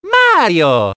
One of Mario's voice clips in Mario Kart 7